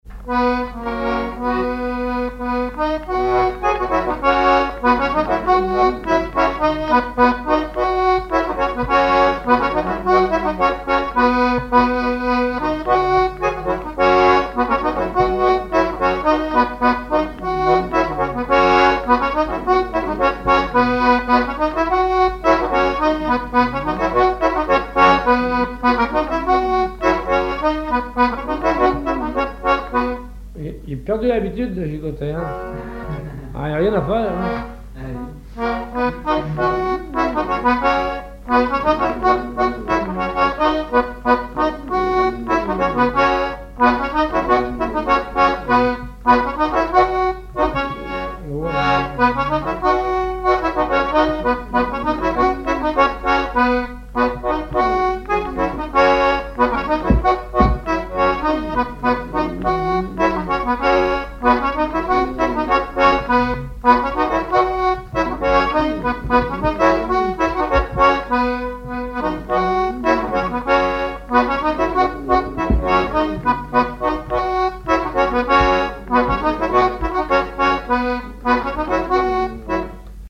Mémoires et Patrimoines vivants - RaddO est une base de données d'archives iconographiques et sonores.
Chants brefs - A danser
danse : scottich trois pas
accordéon diatonique
Pièce musicale inédite